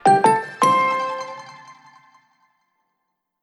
Help your patients remember every dose with the free Joenja reminder tone.
Joenja-Reminder-Tone.wav